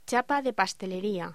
Locución: Chapa de pastelería
voz